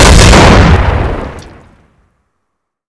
explode3.wav